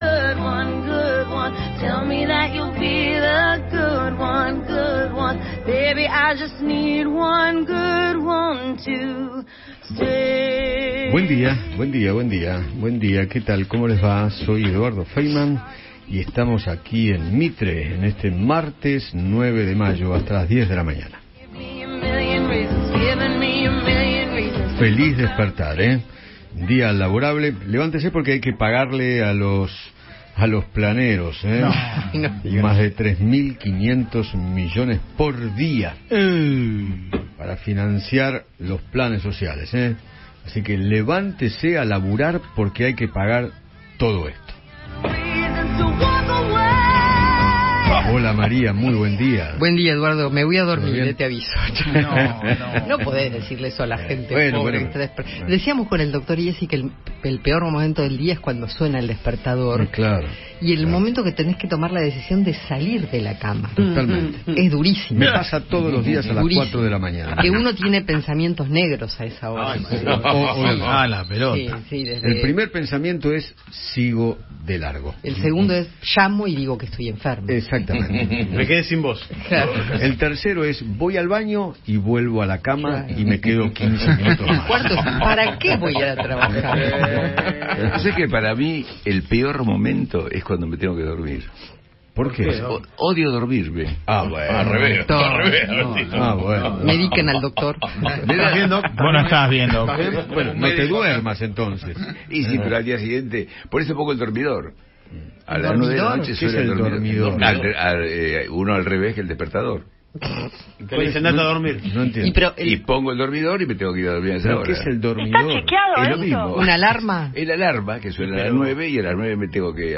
El conductor de Alguien tiene que decirlo reveló la alta cifra que destina el Estado a los planes sociales y lo cuestionó con dureza.